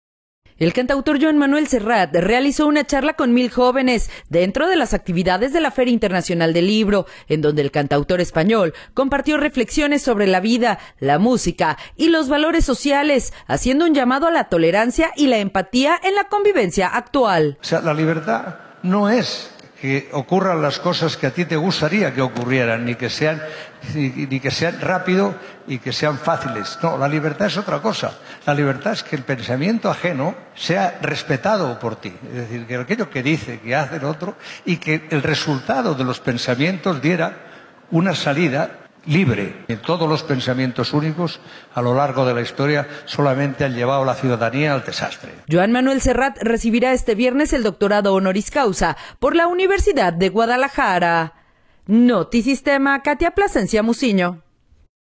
audio El cantautor Joan Manuel Serrat realizó una charla con “Mil jóvenes” dentro de las actividades de la Feria Internacional del Libro, en donde el cantautor español compartió reflexiones sobre la vida, la música y los valores sociales, haciendo un llamado a la tolerancia y la empatía en la convivencia actual.